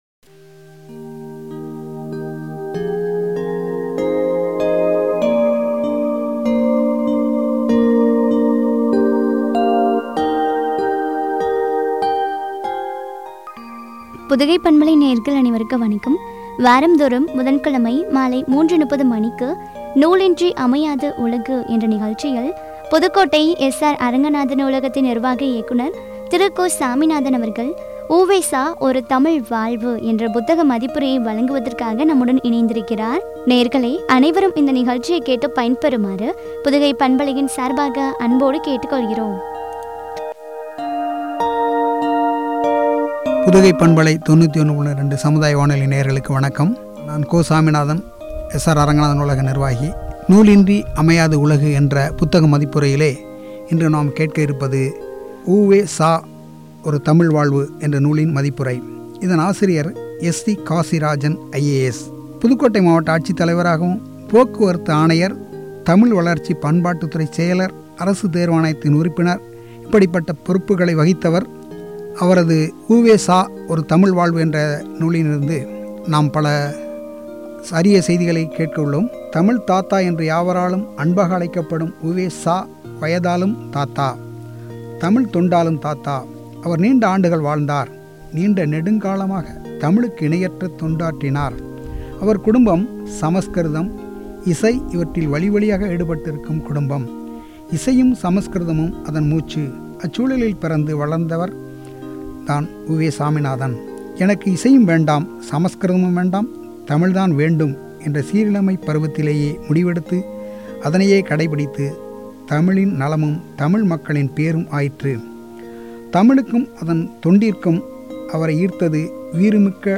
சா ஒரு தமிழ் வாழ்வு” புத்தக மதிப்புரை (பகுதி -10) , குறித்து வழங்கிய உரையாடல்.